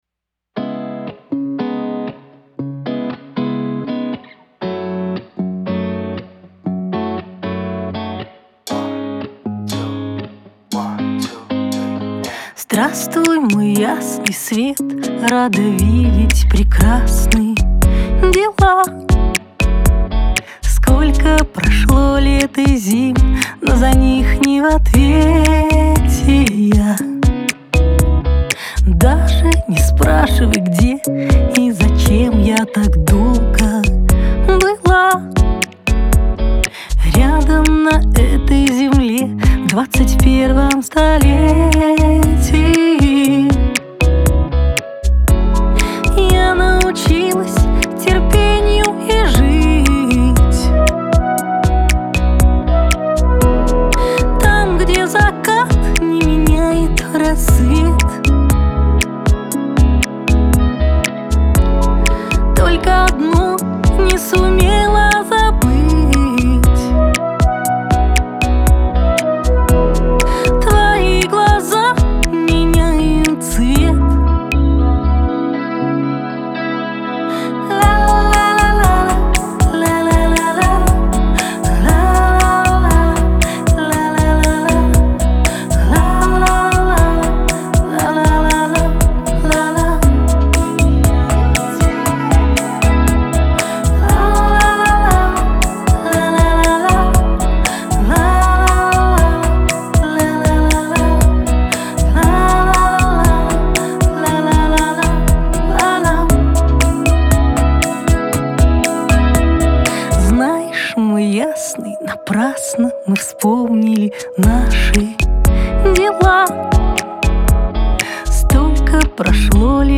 Трек размещён в разделе Русские песни.